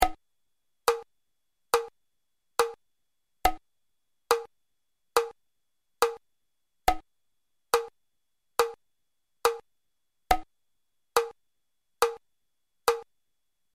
Für die Hörbeispiele habe ich einen einfachen 4/4 Takt gewählt.
adagio
sanft, langsam, ruhig
BPM: 70